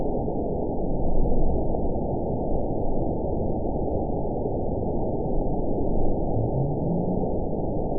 event 919862 date 01/26/24 time 08:46:18 GMT (1 year, 4 months ago) score 9.39 location TSS-AB07 detected by nrw target species NRW annotations +NRW Spectrogram: Frequency (kHz) vs. Time (s) audio not available .wav